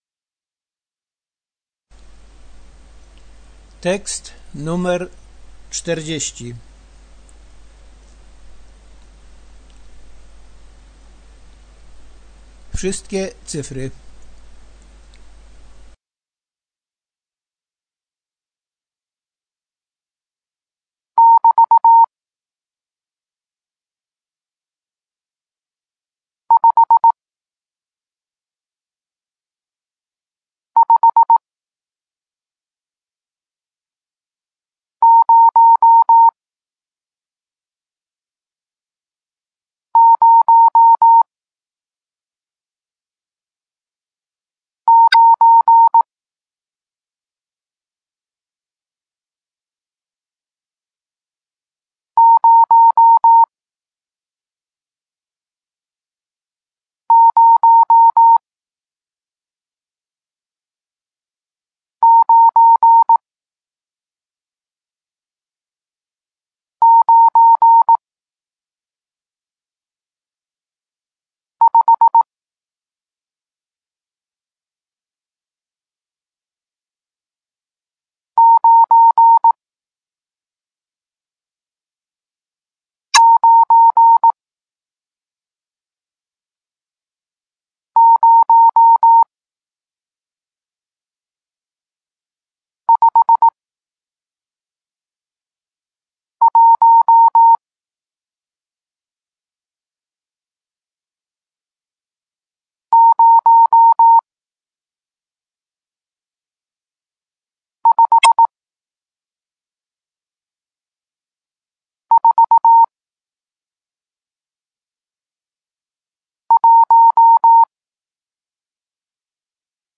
( . ) kropka ti-taaa-ti-taaa-ti-taaa ( , ) przecinek taaa-taaa-ti-ti-taaa-taaa ( ? ) znak zapytania ti-ti-taaa-taaa-ti-tit ( = ) rozdział taaa-ti-ti-ti-ti-taaa